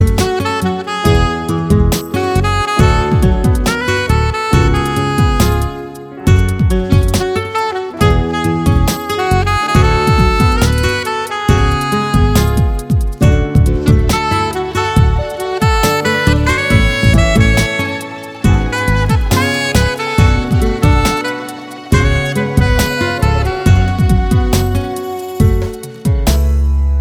саксофон